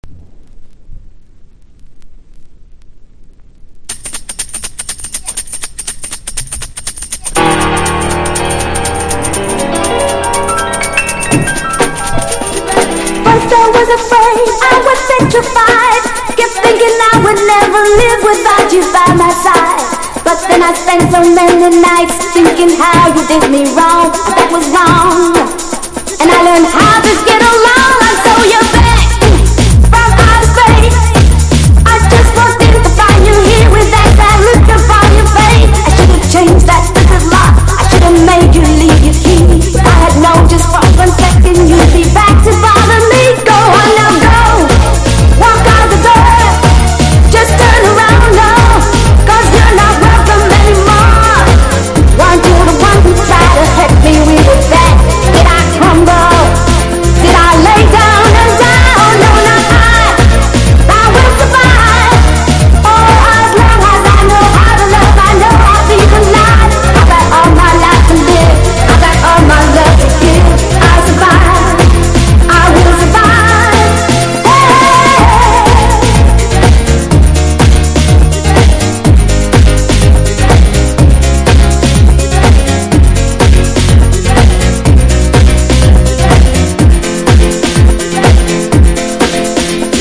90'S VOCAL HOUSE!(TR 1959)